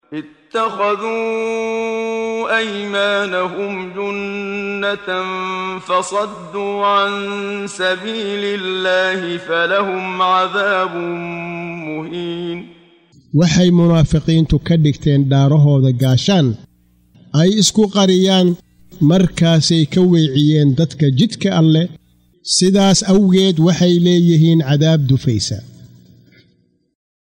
Waa Akhrin Codeed Af Soomaali ah ee Macaanida Suuradda Al-Mujaadilah ( doodeysa ) oo u kala Qaybsan Aayado ahaan ayna la Socoto Akhrinta Qaariga Sheekh Muxammad Siddiiq Al-Manshaawi.